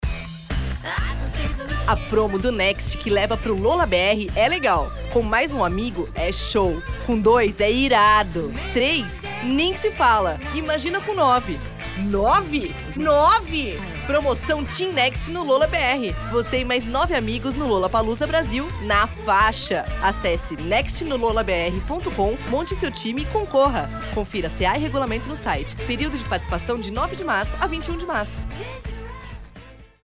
Feminino
BRADESCO LOLLAPALOOZA (jovem, informal)
Voz Jovem 00:30